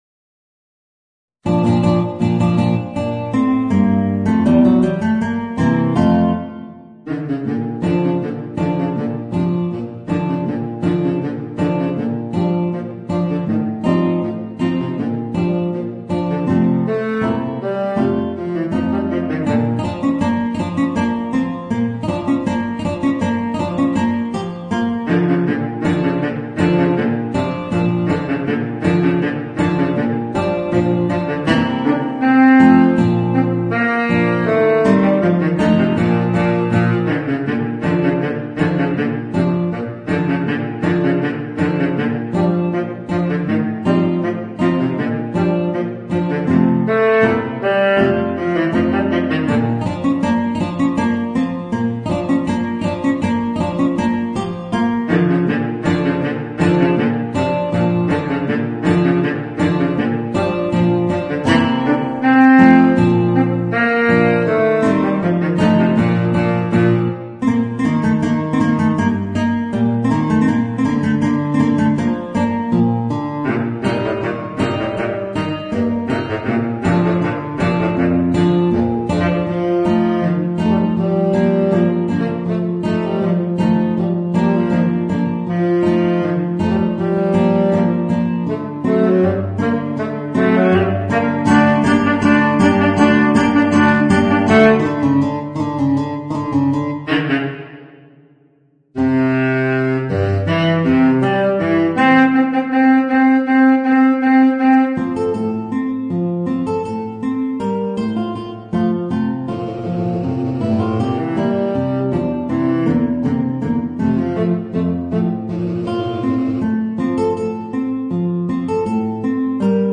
Voicing: Guitar and Baritone Saxophone